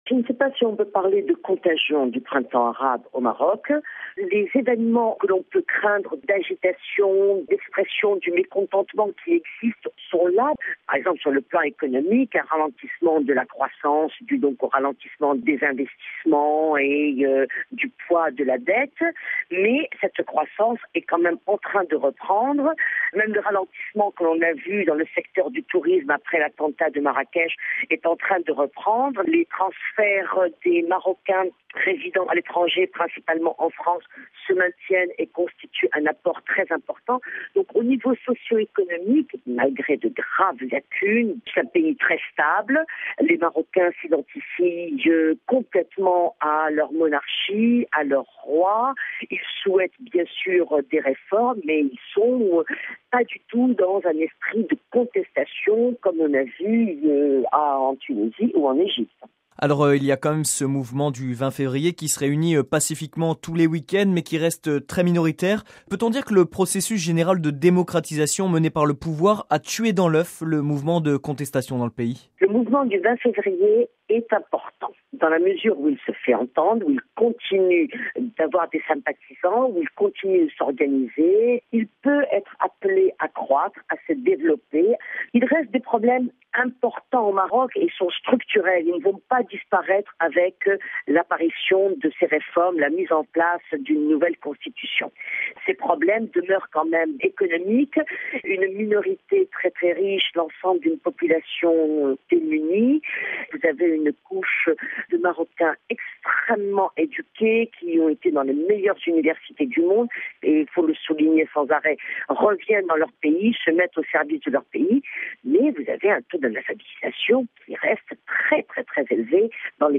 Est-ce par peur d’une contagion du « printemps arabe » ? L’analyse